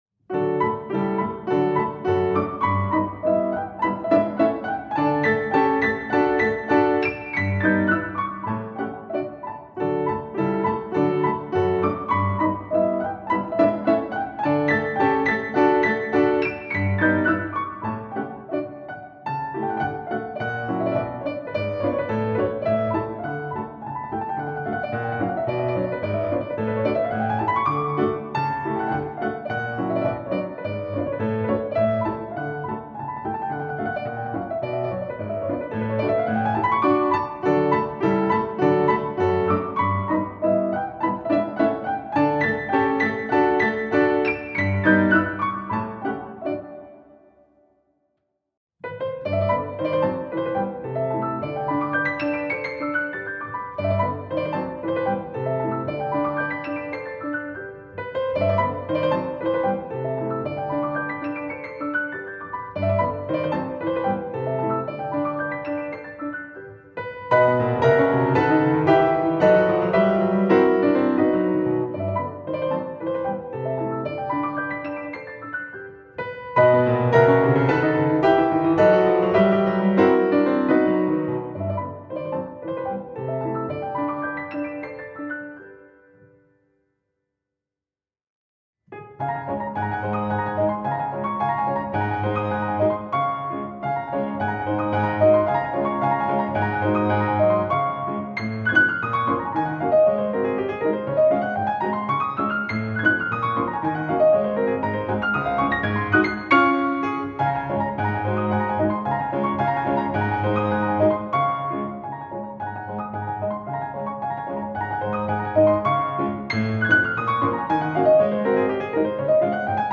No parts available for this pieces as it is for solo piano.
Piano  (View more Intermediate Piano Music)
Classical (View more Classical Piano Music)